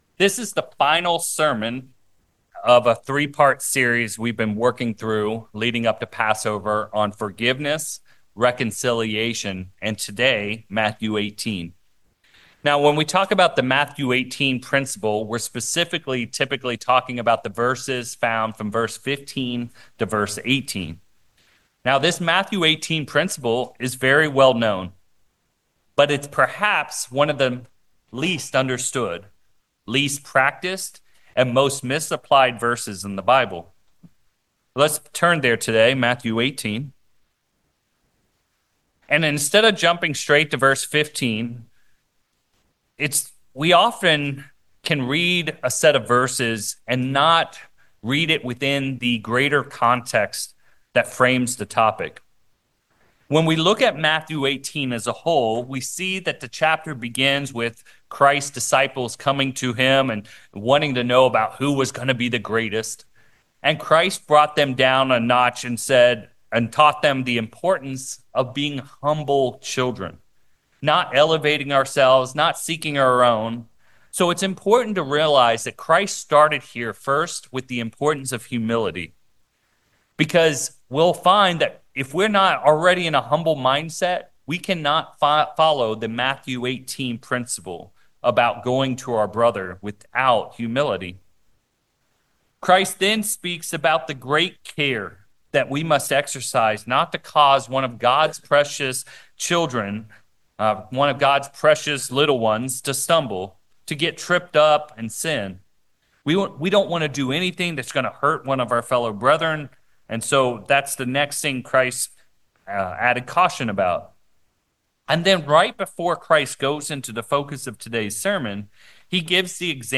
Sermons
Given in San Francisco Bay Area, CA San Jose, CA Petaluma, CA